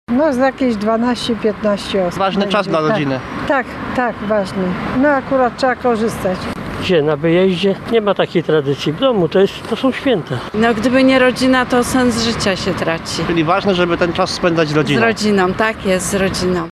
Zapytaliśmy gdańszczan, jak spędzają święta Bożego Narodzenia